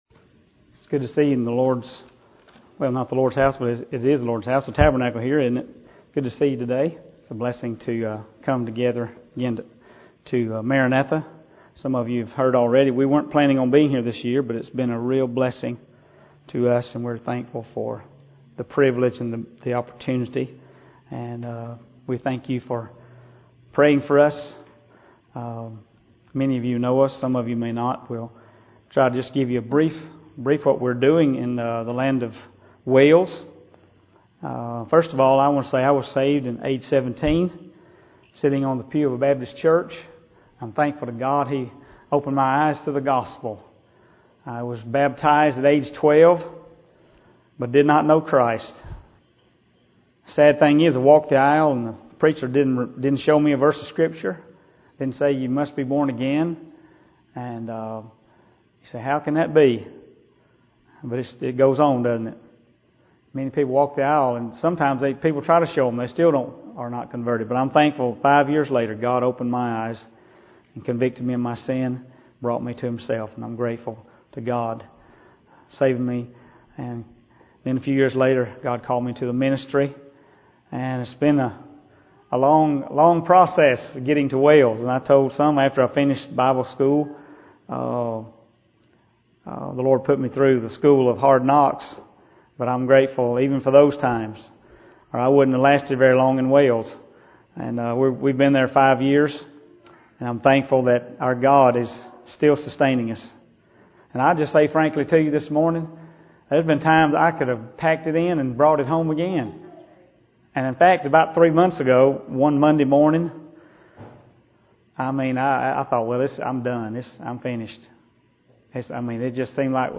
Session: Morning Devotion